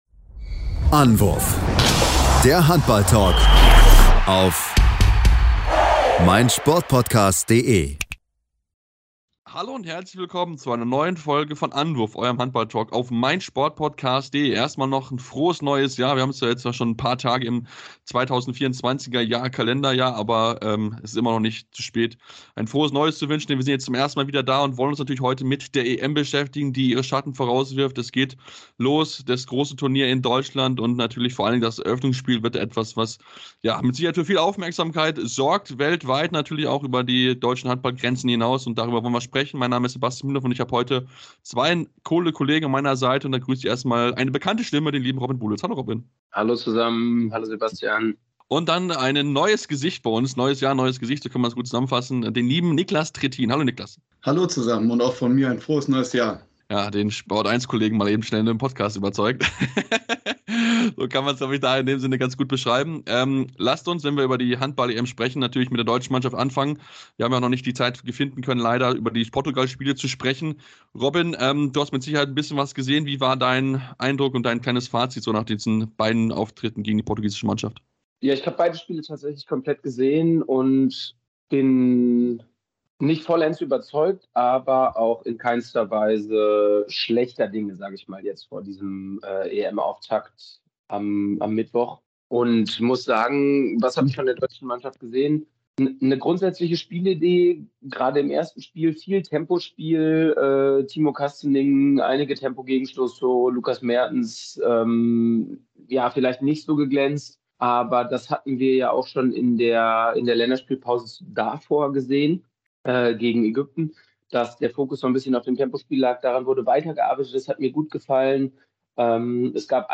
Die große EM-Vorschau & Interview